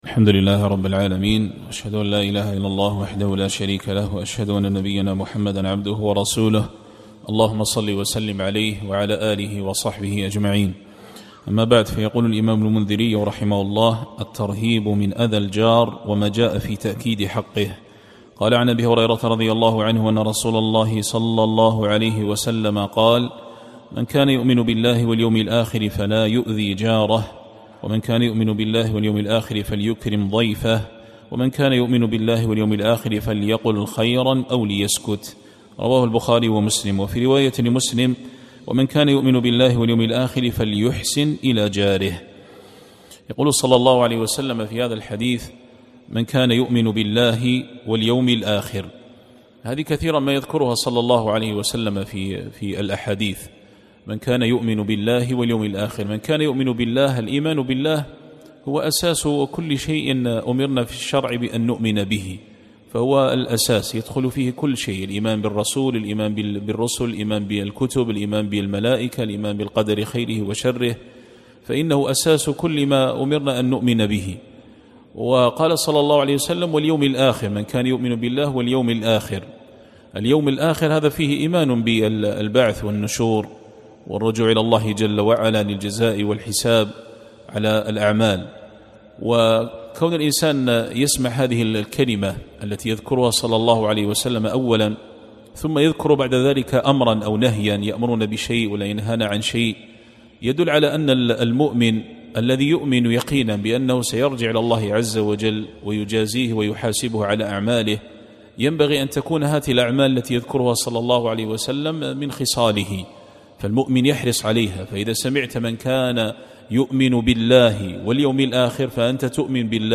الدرس الرابع والثلاثون-34-